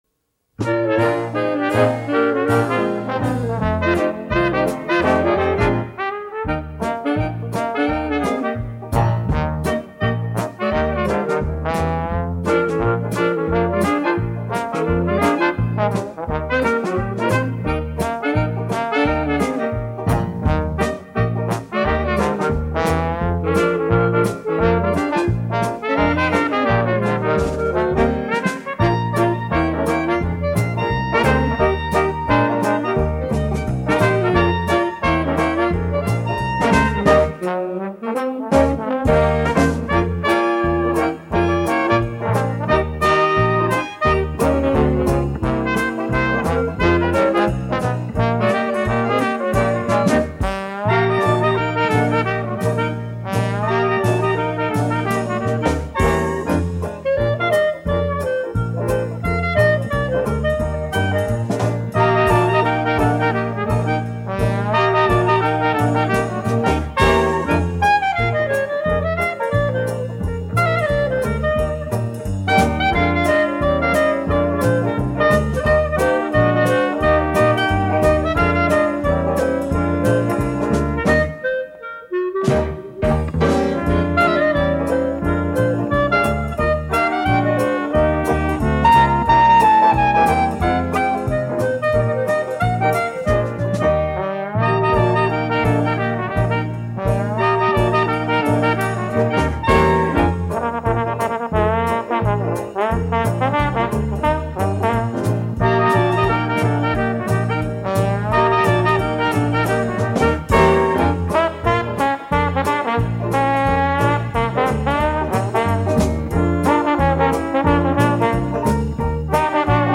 Arrangiamenti Dixie